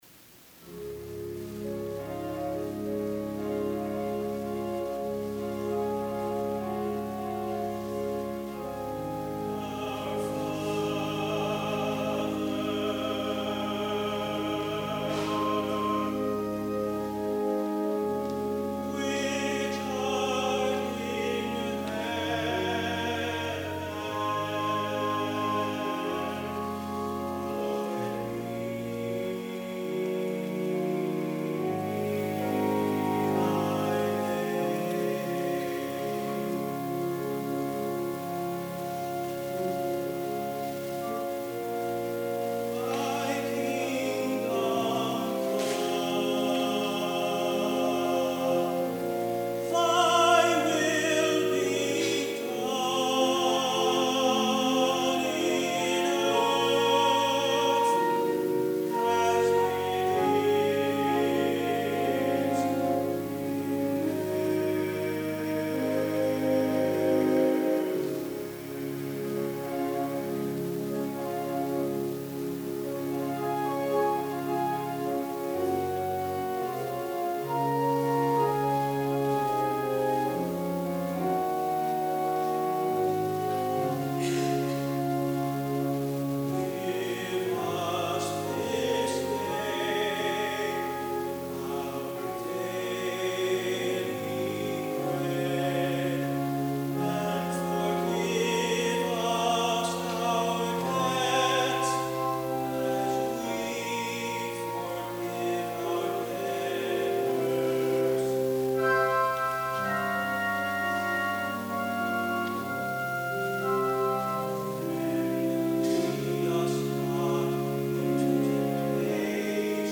SOLO The Lord’s Prayer Albert Hay Malotte
tenor
organ